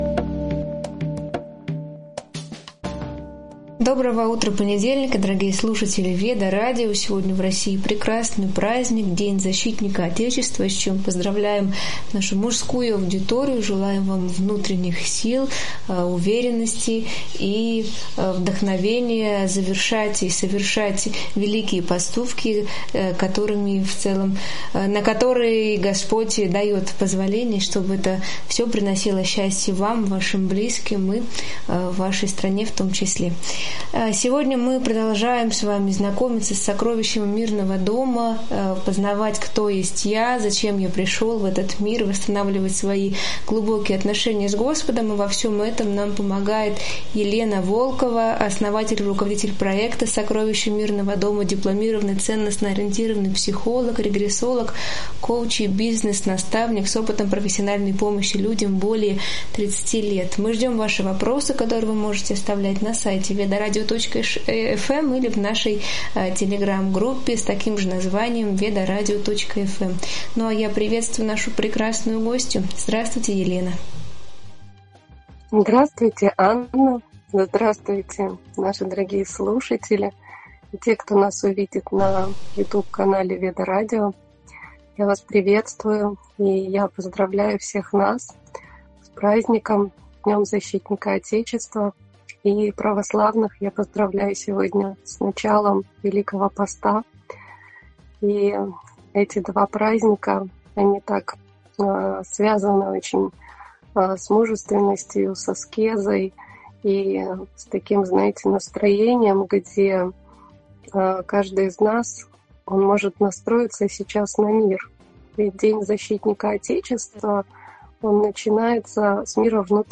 Эфир посвящён защите внутреннего мира, личным границам и истинному служению.